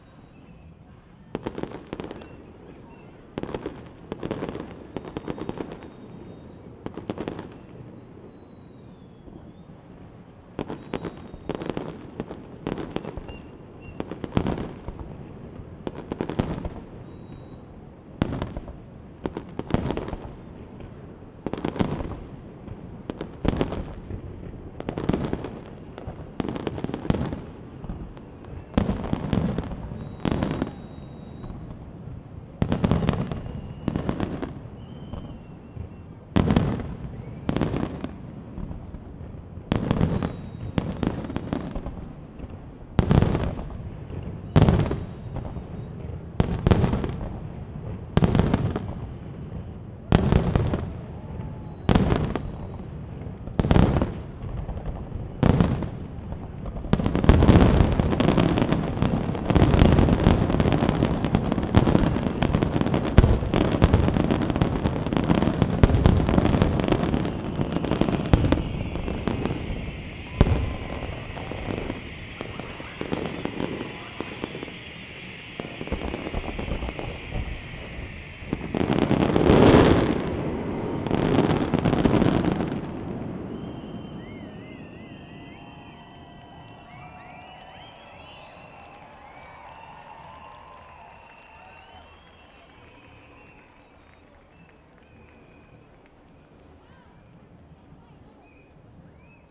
fireworks_mono.wav